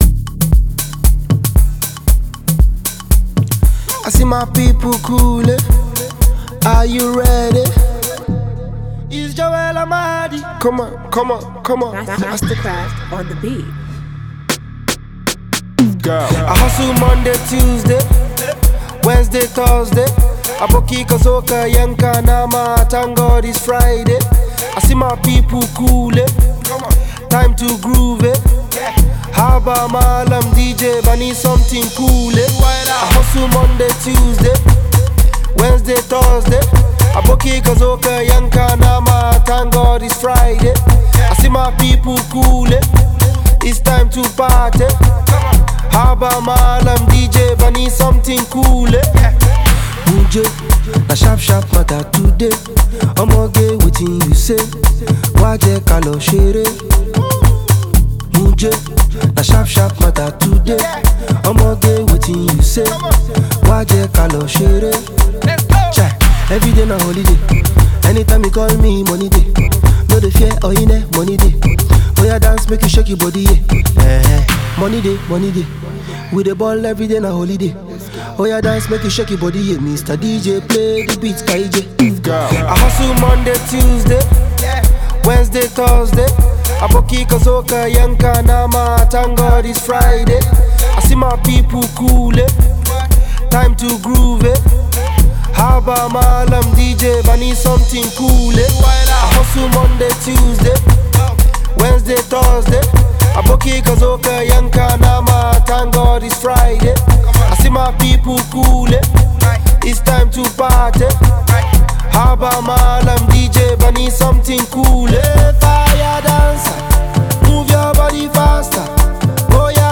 Afrobeat Banger
amazing dance song like no other
Listen to the banging tune below: